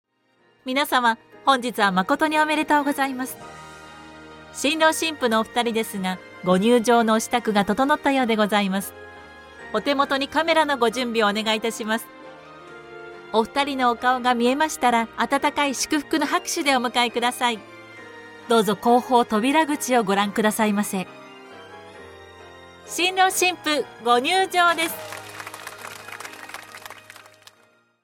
司会のボイスサンプルです。
新郎新婦入場シーン
host-bridal1-2.mp3